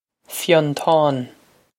Fionntán Fyun-tahn
Pronunciation for how to say
This is an approximate phonetic pronunciation of the phrase.